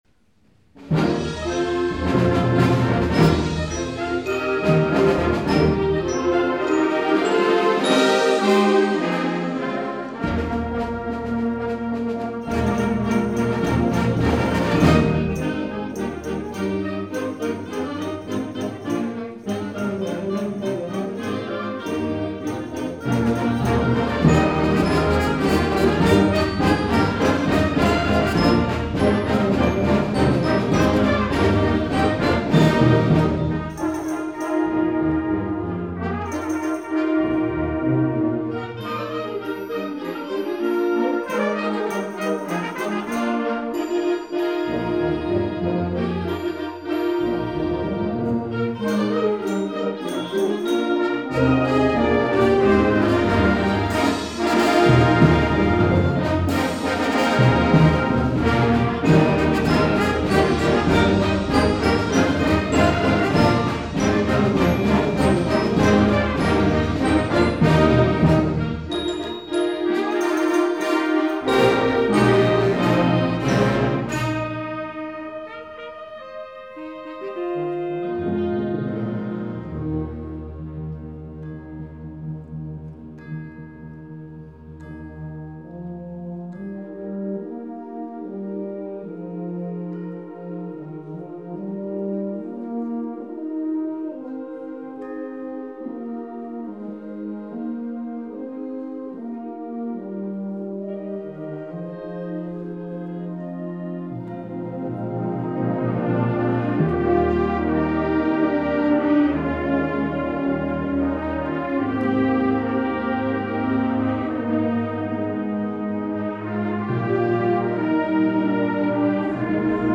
2012 Summer Concert